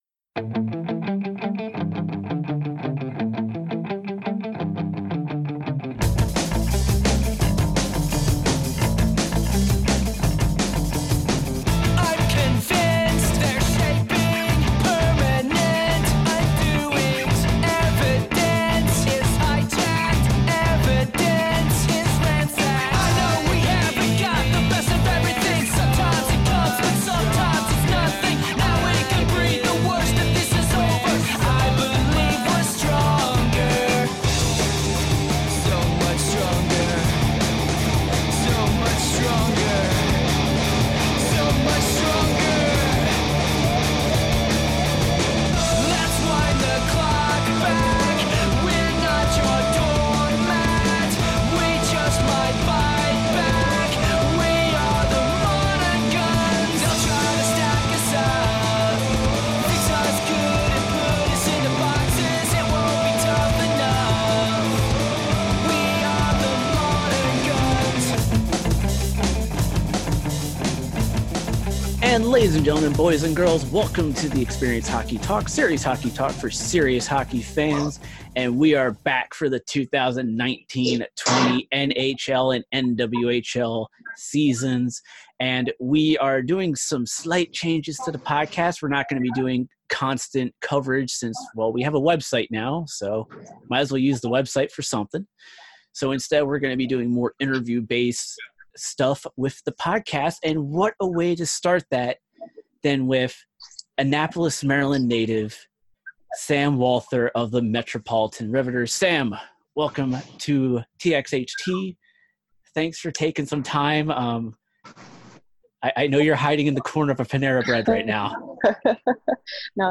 TXHT Podcast Interview